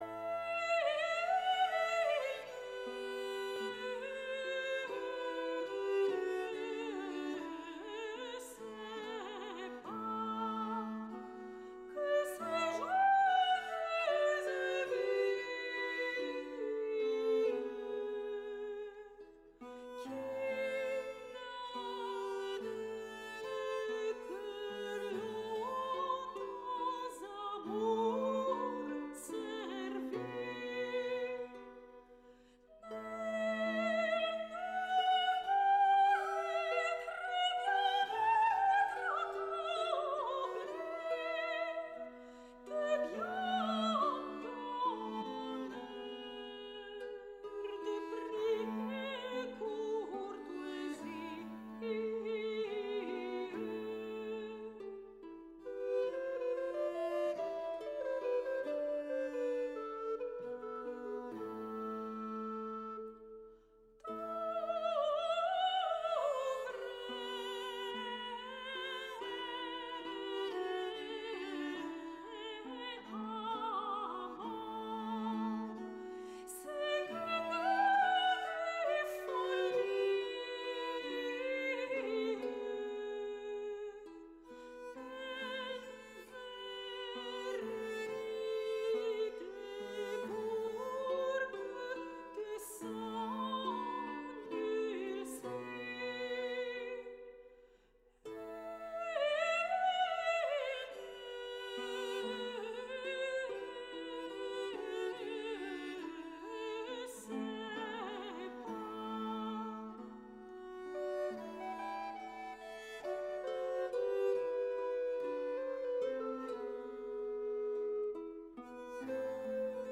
Η ΦΩΝΗ ΤΗΣ ΕΛΛΑΔΑΣ Infinitely Curious Shows in English Πολιτισμός ΣΥΝΕΝΤΕΥΞΕΙΣ Συνεντεύξεις